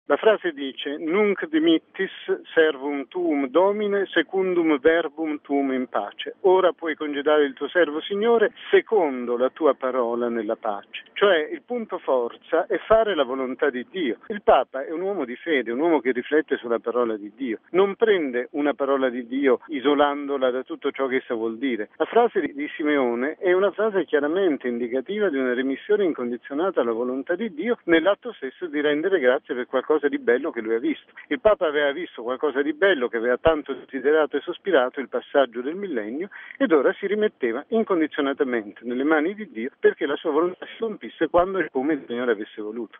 Non si tratta però di una rinuncia, ma di un rendimento di grazie a Dio, per averlo sostenuto alla guida della Chiesa nel passaggio al nuovo millennio. Ce lo spiega mons. Bruno Forte, arcivescovo di Chieti-Vasto: RealAudio